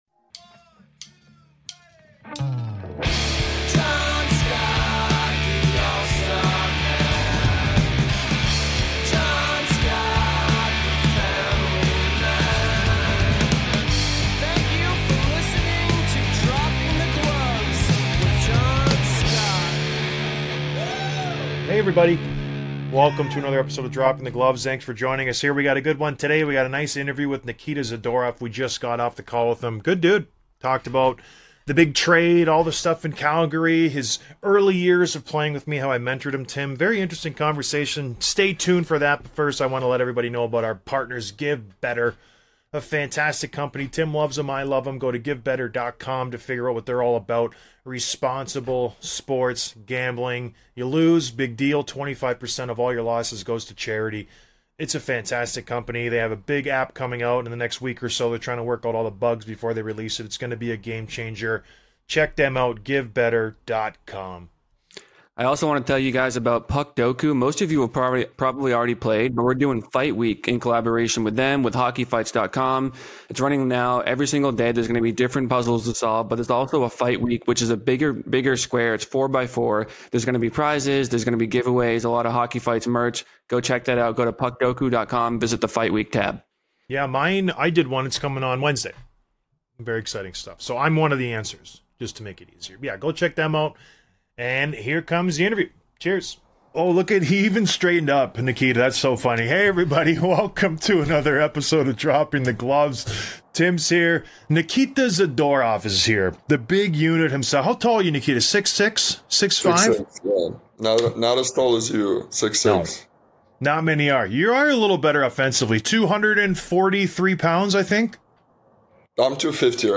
Interview with Nikita Zadorov, Vancouver Canucks